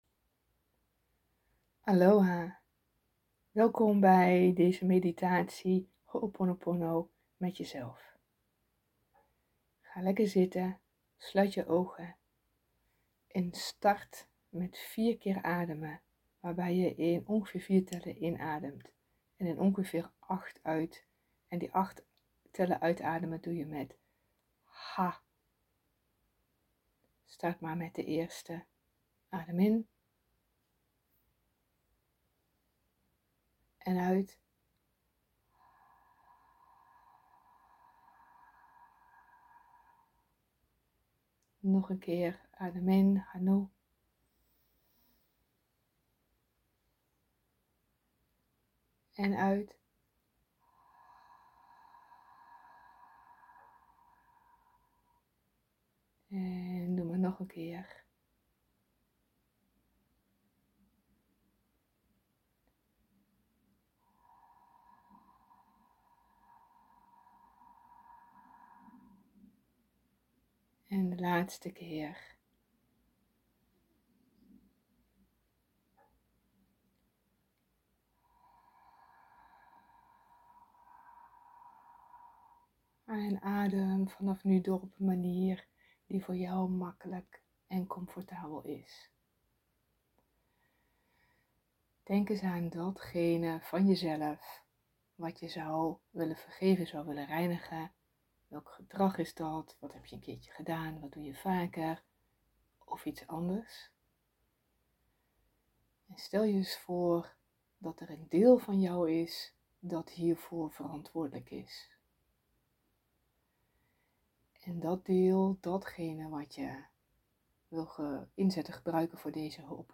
Meditatie Ho’oponopono, een reinigingsritueel, met jezelf